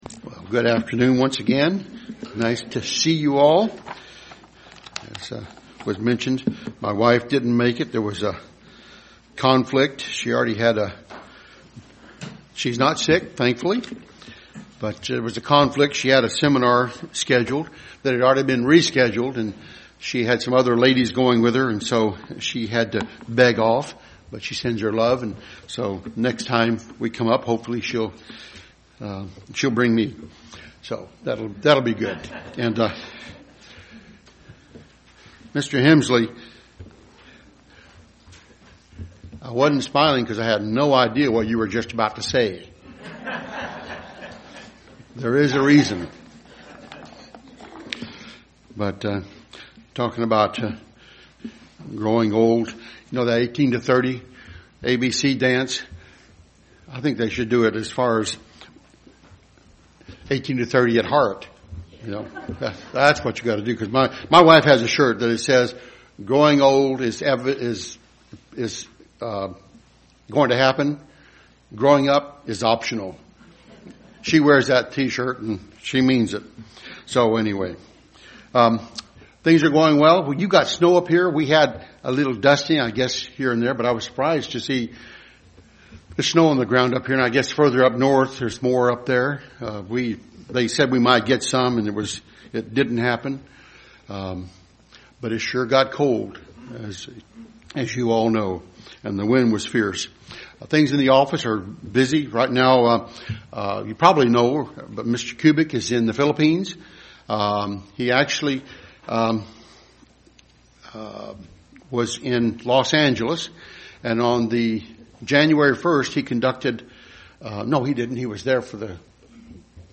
There are Three Certain Areas We Are To Sure Up In Our Lives. Listen to this sermon to see what they are.
Given in Dayton, OH